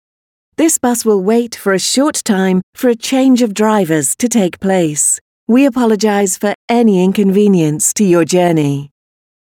driverchange.mp3